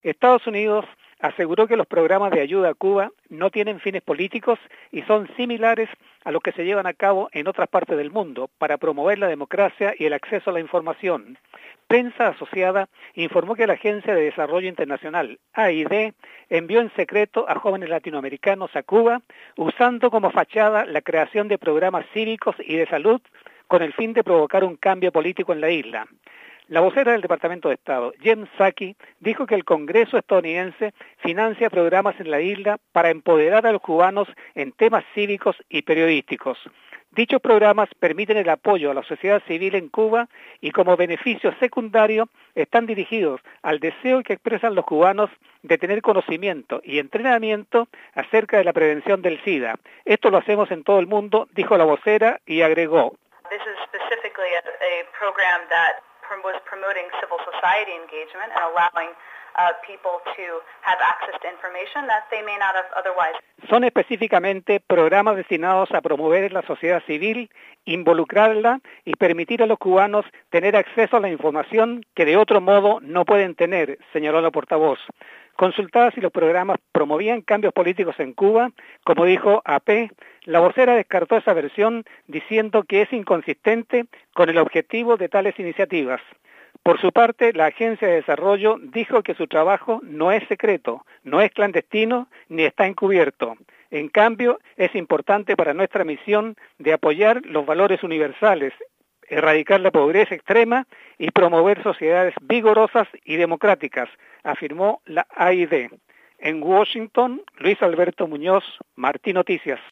reporta desde la ciudad de Washington...